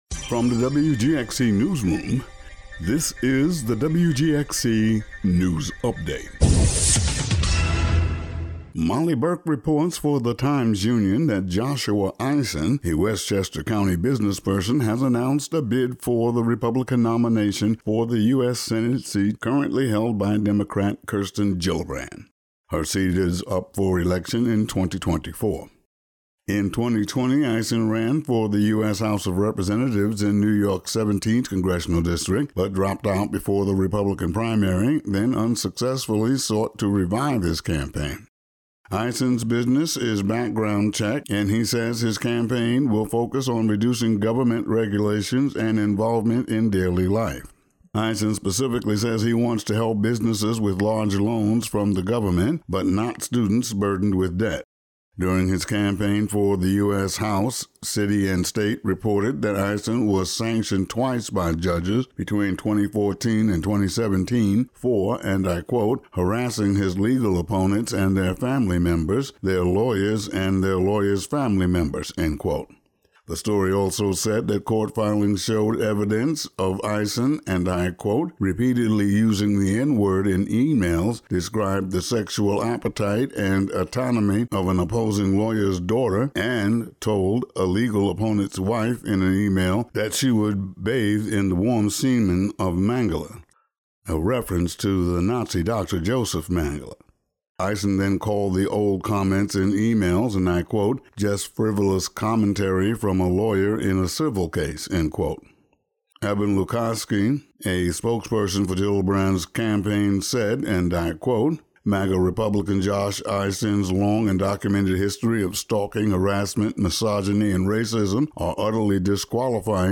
Today's daily local audio news update.